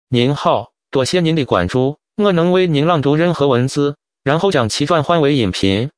Professionelle Sprachausgabe zum Vorlesen und Vertonen beliebiger Texte
Die Stimmen klingen so natürlich, dass sie von menschlichen Sprechern kaum noch zu unterscheiden sind.
• Die Text-to-Speech Software bietet Ihnen alles, was Sie für die professionelle Vertonung benötigen